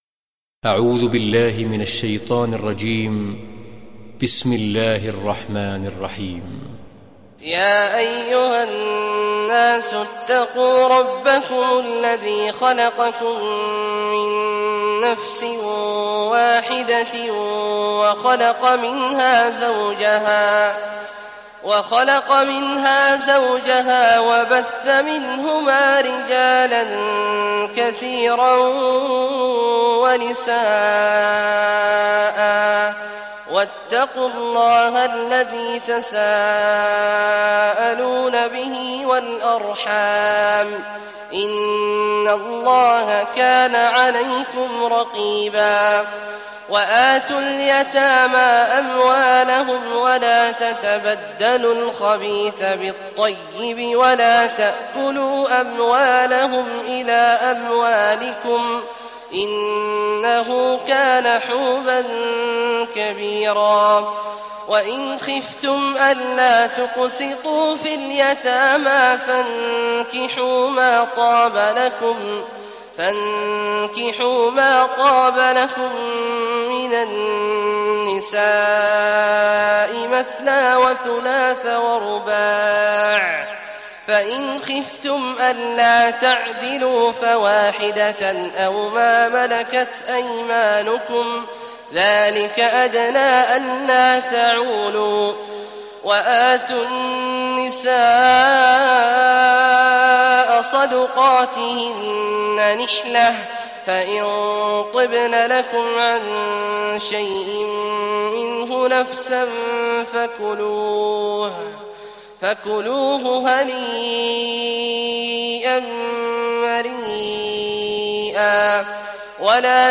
Surah Nisa Recitation Sheikh Awad Juhany
Surah Nisa, listen or play online mp3 tilawat / recitation in Arabic in the beautiful voice of Sheikh Awad al Juhany.